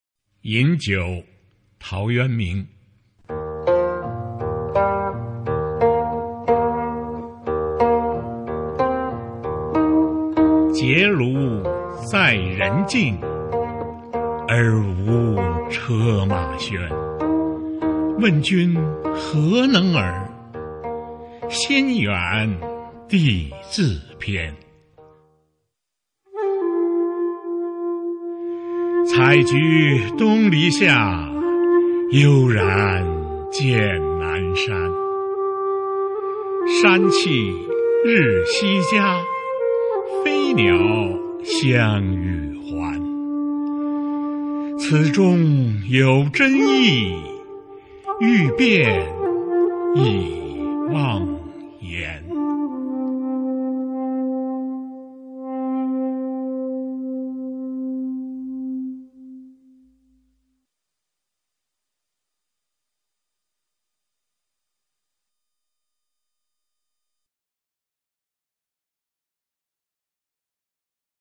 经典朗诵欣赏 群星璀璨：中国古诗词标准朗读（41首） 目录